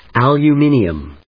音節al・u・min・i・um 発音記号・読み方
/`æljʊmíniəm(米国英語), ʌˈlu:mɪnʌm(英国英語)/
フリガナアルーミナム